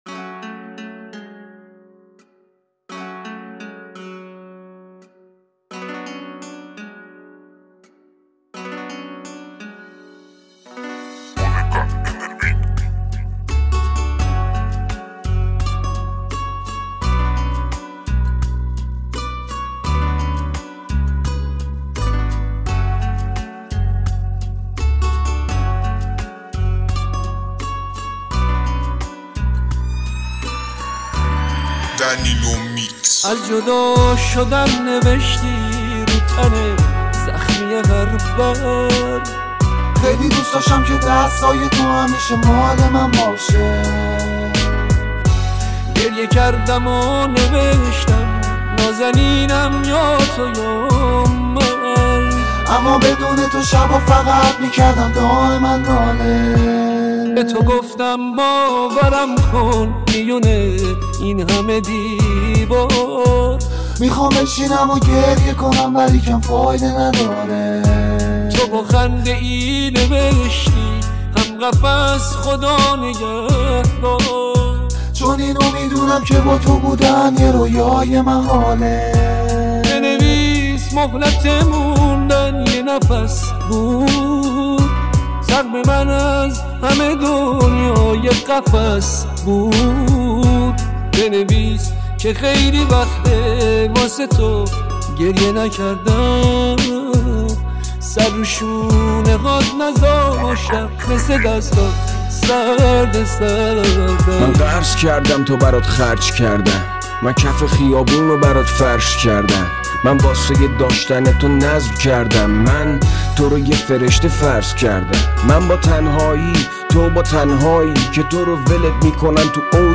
Remix Rapi